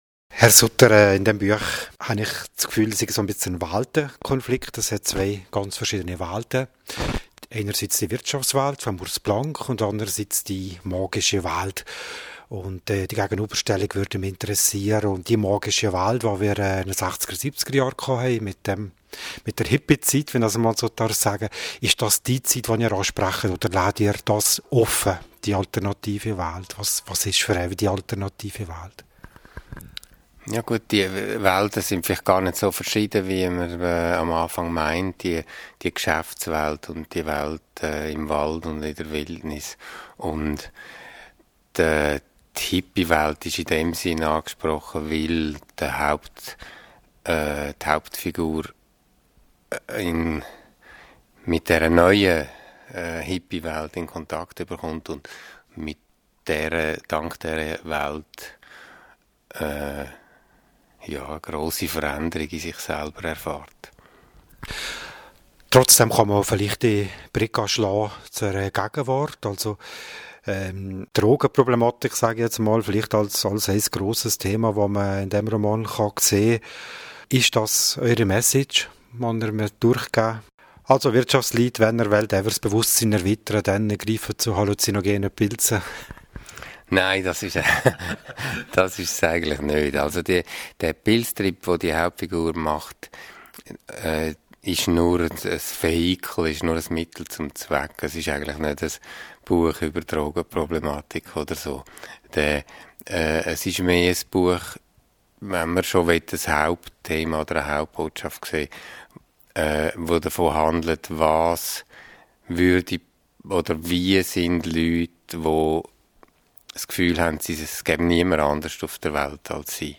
Im Gespräch mit Schriftsteller Martin Suter
Das ganze Interview finden sie als audio zu diesem Literaturwälla-Beitrag.